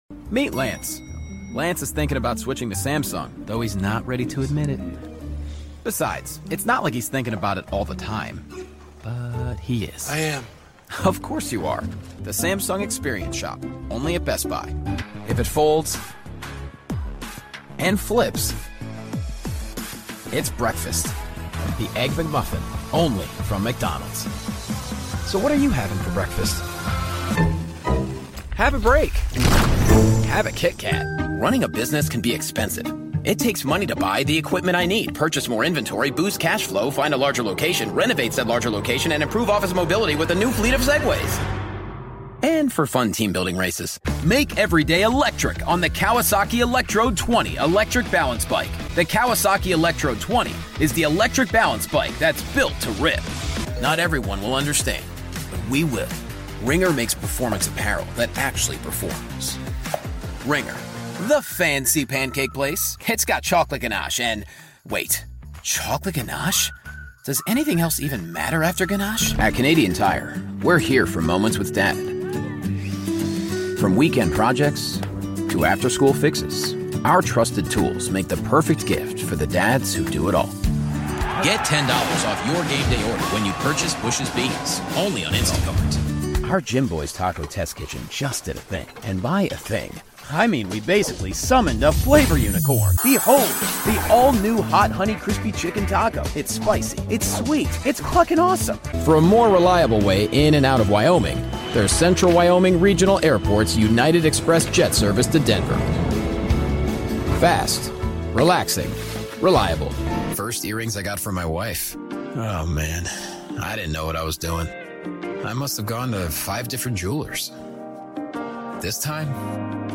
Find and hire professional voice over artists in the Funabashi.
Male 30s , 40s , 50s American English (Native) , Flemish (Native) Approachable , Assured , Authoritative , Bright , Character , Confident , Conversational , Cool , Corporate , Deep , Energetic , Engaging , Friendly , Funny , Gravitas , Natural , Posh , Reassuring , Sarcastic , Smooth , Soft , Upbeat , Versatile , Wacky , Warm , Witty